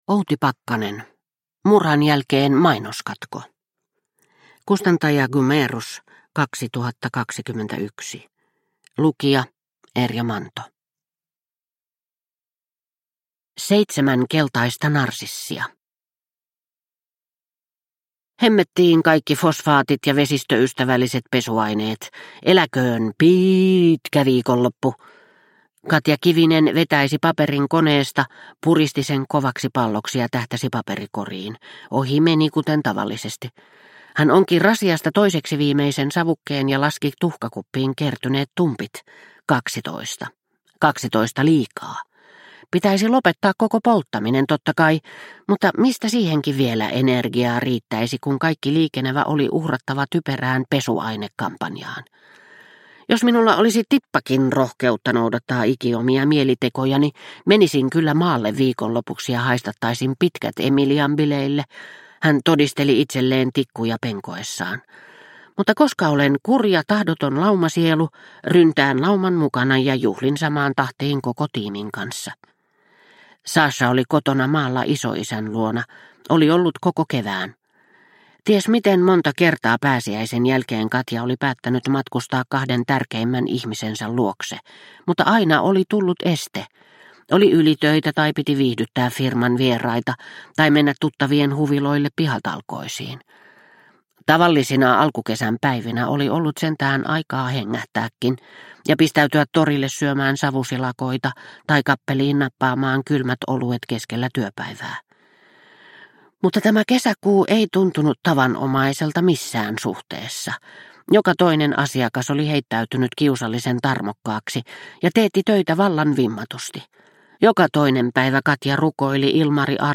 Murhan jälkeen mainoskatko – Ljudbok – Laddas ner